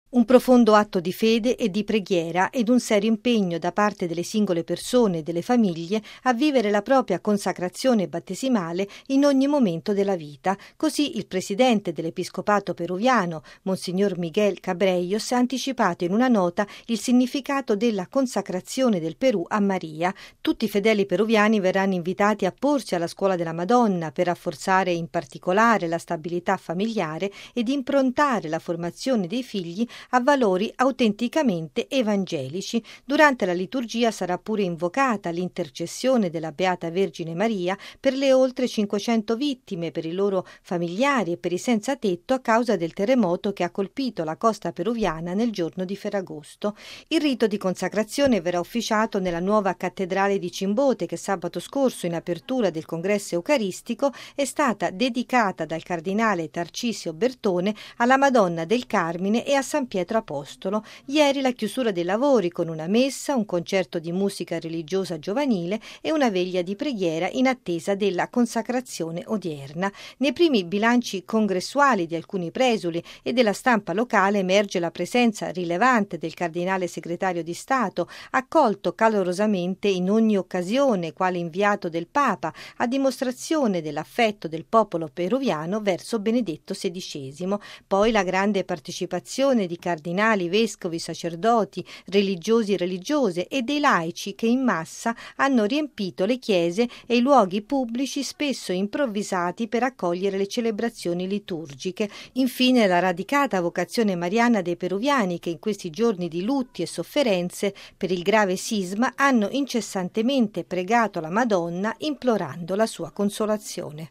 L’intero episcopato peruviano ed i vescovi ospiti latinoamericani e di altre aree parteciperanno subito dopo alla processione del Santissimo Sacramento e poi all’atto di Consacrazione del Paese alla Vergine Maria. Il servizio